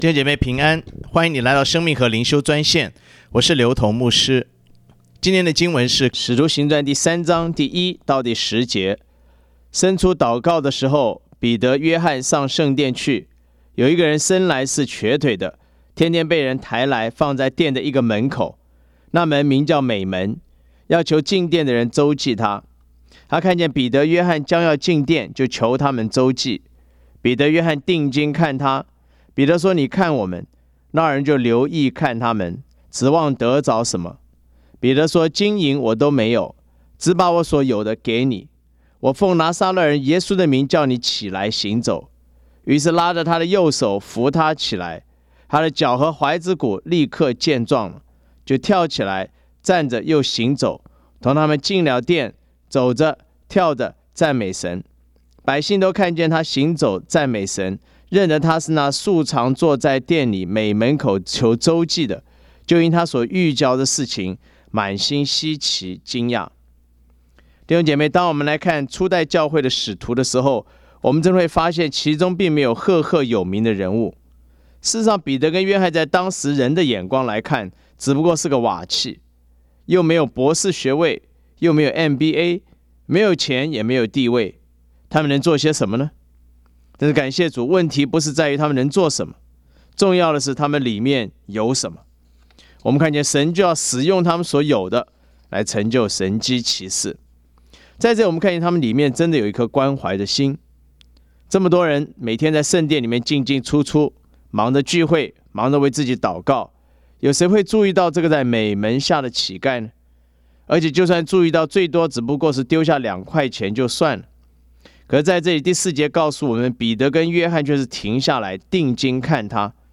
藉着每天五分钟电话分享，以生活化的口吻带领信徒逐章逐节读经。